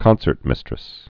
(kŏnsərt-mĭstrĭs)